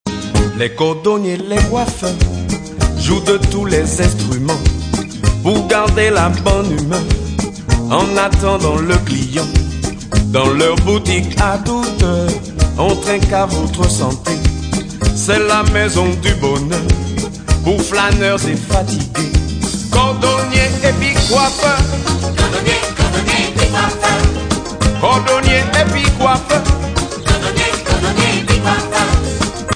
Batterie
Choeurs